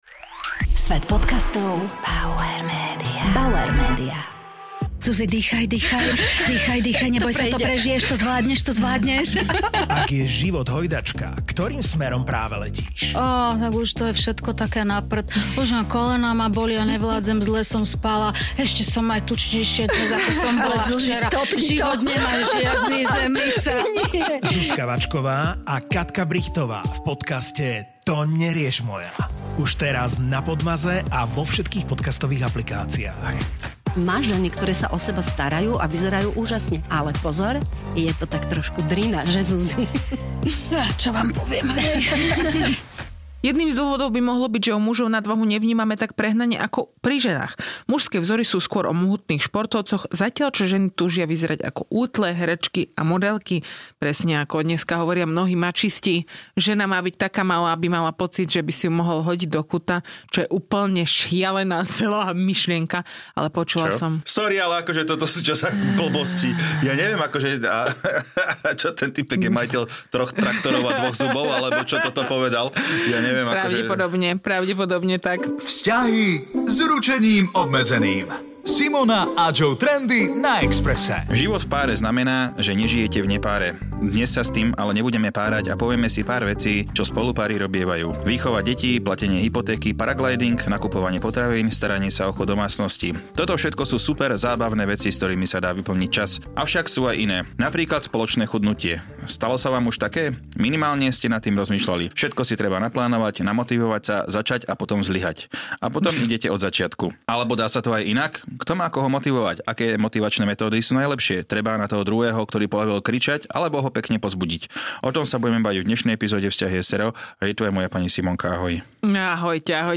dvaja komici, ktorí tvoria pár